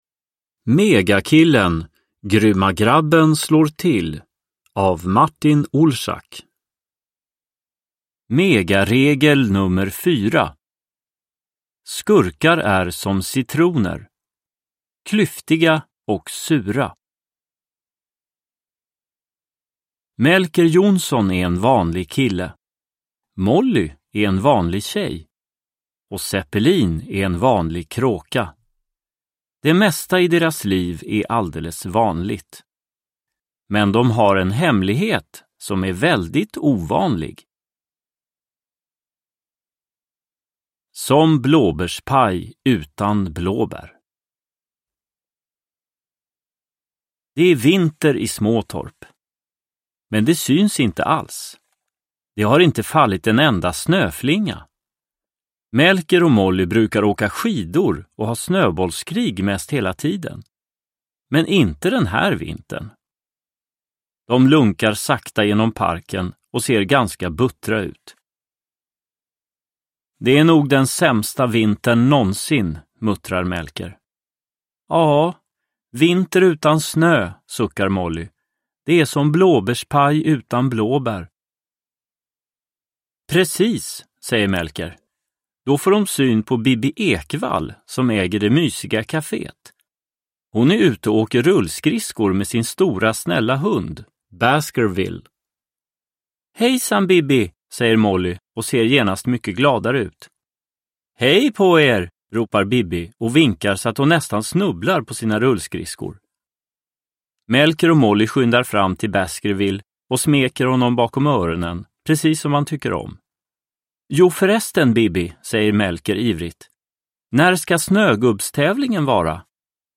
Megakillen. Grymma Grabben slår till – Ljudbok – Laddas ner